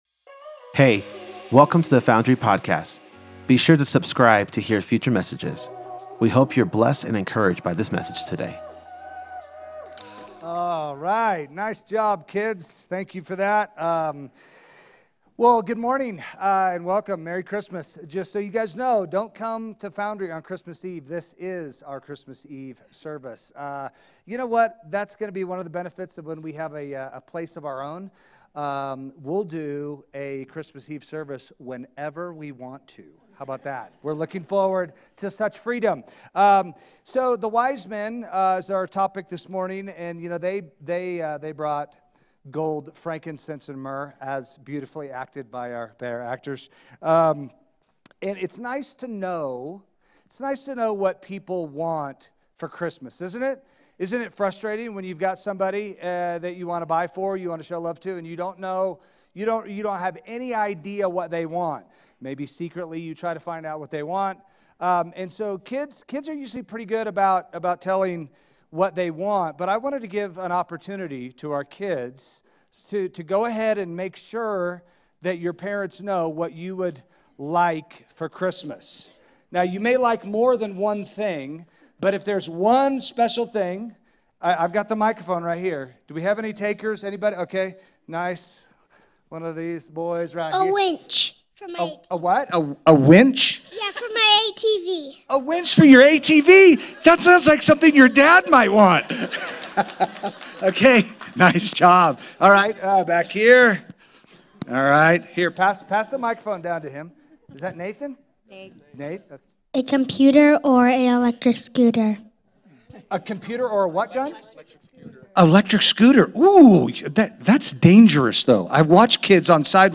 Candlelight Service 2025: The Magi's Worship